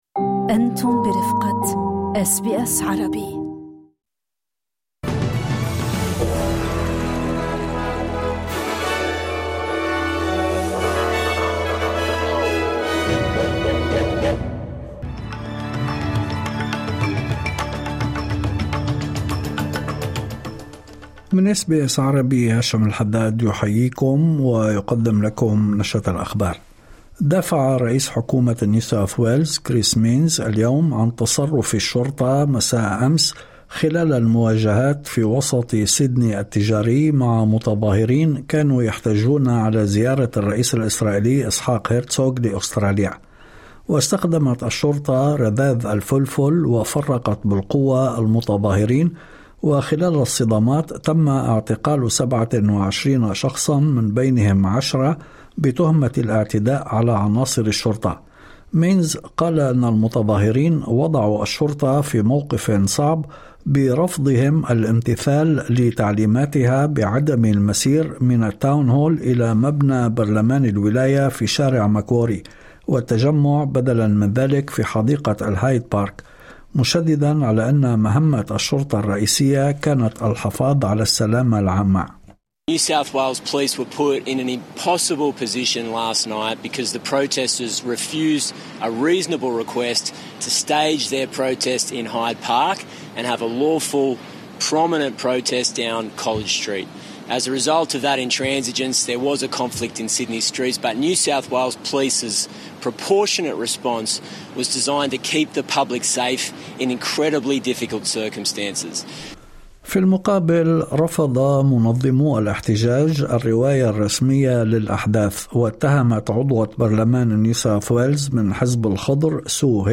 نشرة أخبار الظهيرة 10/02/2026